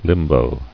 [lim·bo]